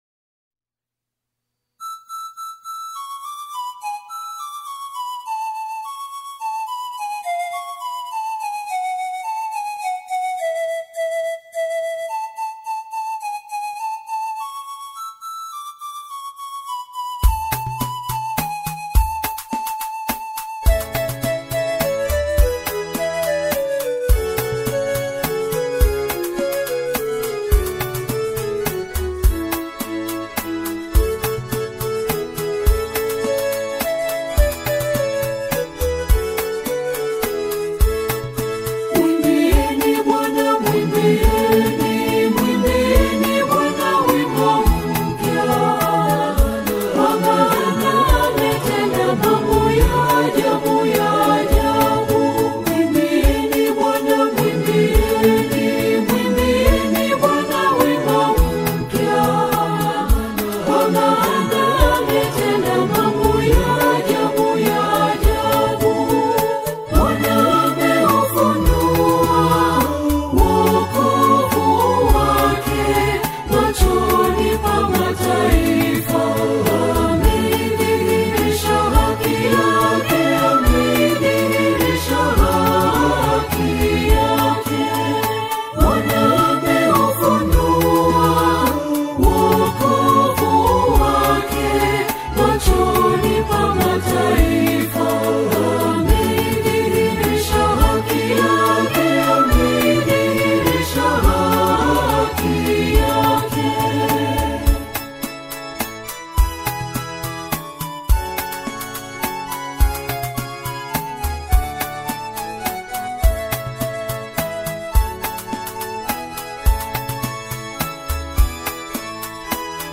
entrance song
gospel song